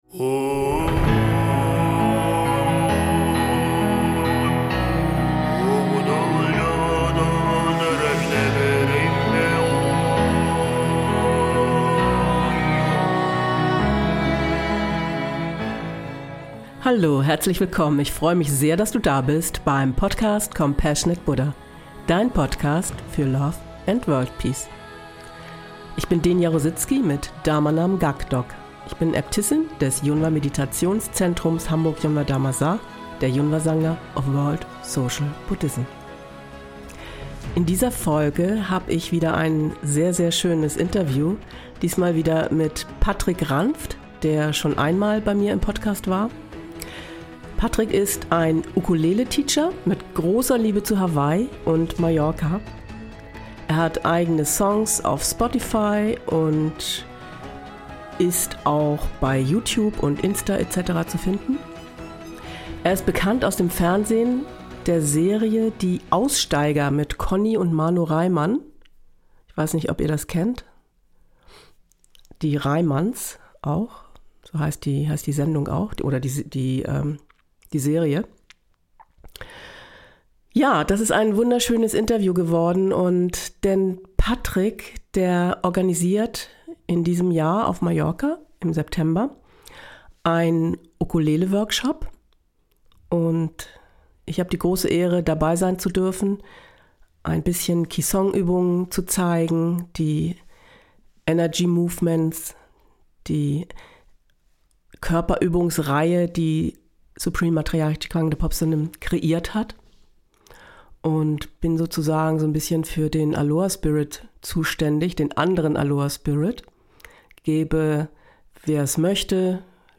Außerdem lese ich Ausschnitte aus Daily Remindern von Ji Kwang Dae Poep Sa Nim über Körperübungen vor, da ich auf Mallorca auch Ki Song Körper-Übungen zeigen werde.